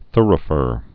(thrə-fər)